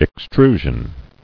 [ex·tru·sion]